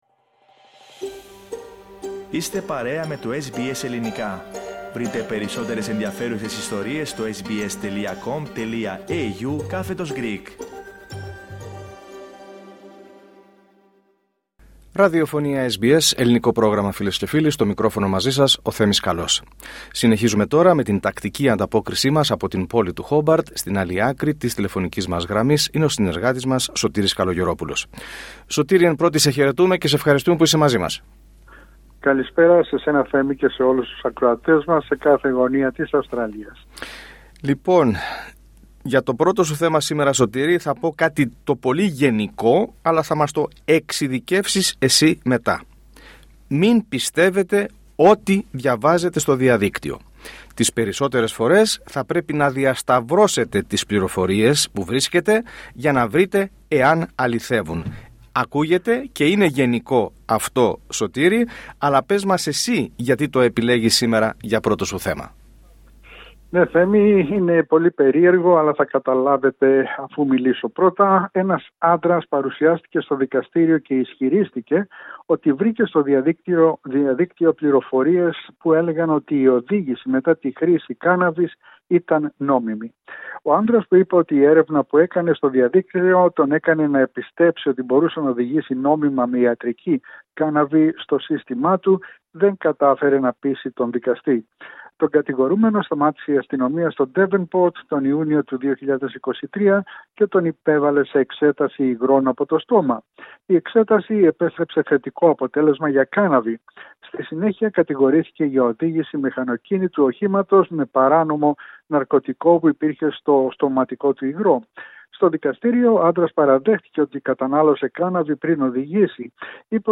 *Κάντε κλικ στο ηχητικό για να ακούσετε όλη την ανταπόκριση από την Τασμανία.